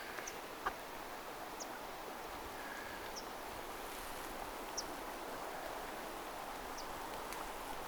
keltasirkkulintu lentää läheltä
keltasirkkulintu_lentaa_matkalentoa_lahelta.mp3